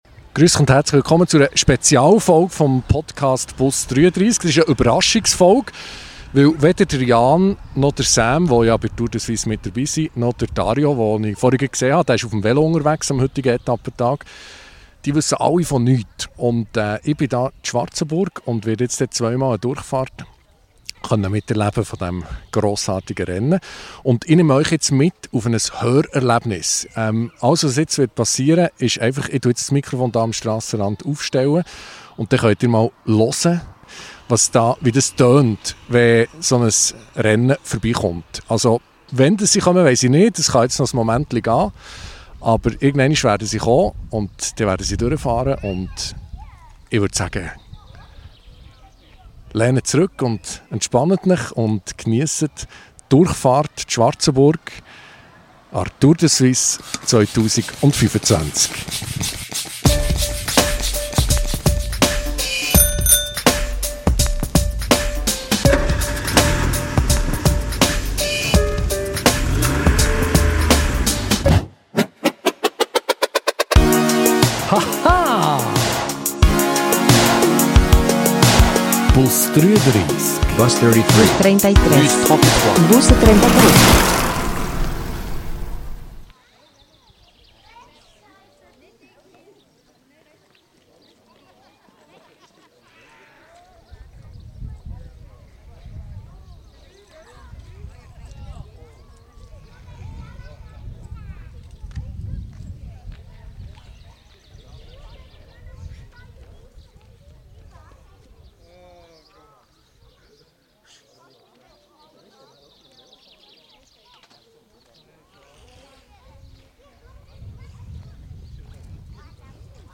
Wie klingt es, wenn ein Velorennen durch ein Dorf fährt?
So viel Stimmung und so wenig Inhalt - nur hier! (7:02 Horni // 7:15 Durchsage // 9:35 Durchsage 2 // 9:48 Horni LAUT // 12:39 Spitzengruppe // 13:55 Peloton bzw. Helikopter) Mehr